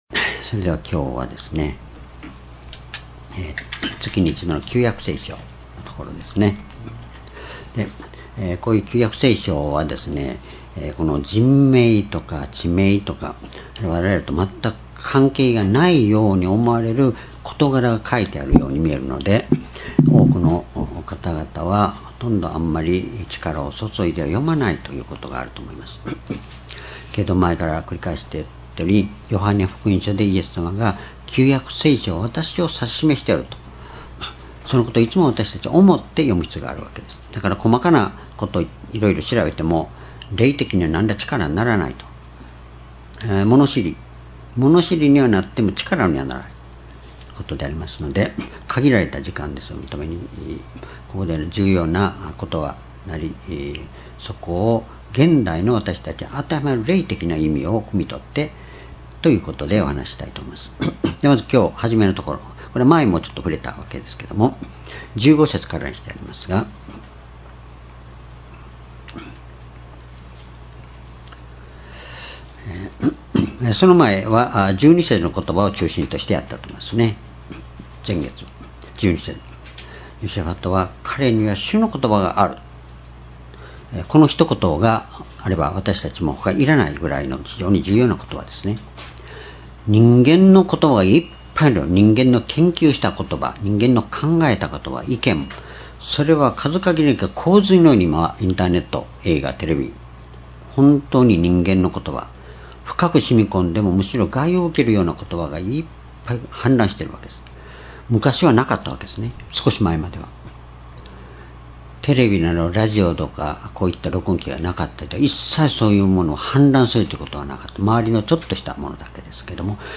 主日礼拝日時 2018年8月5日 主日 聖書講話箇所 「讃美の力と満たす神」 列王記下3章15節～20節 ※視聴できない場合は をクリックしてください。